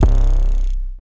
808 [Gucci necklace over me].wav